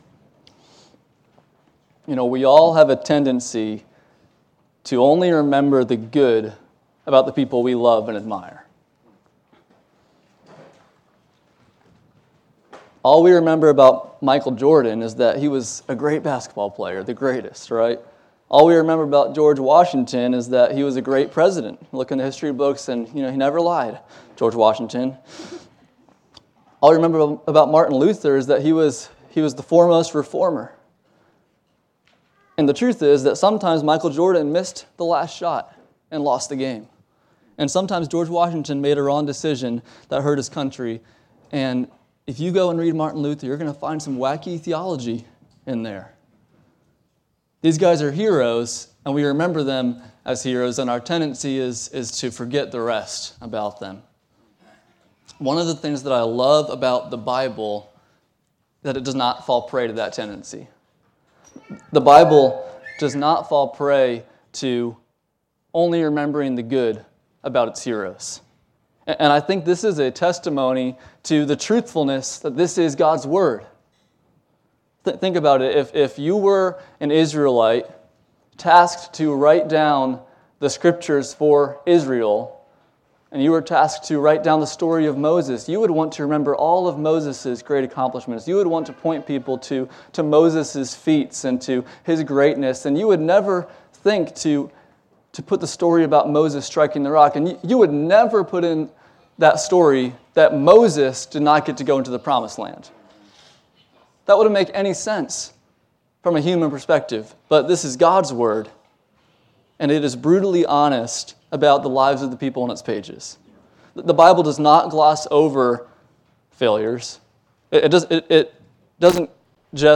Passage: 2 Samuel 23:1-24:25 Service Type: Sunday Morning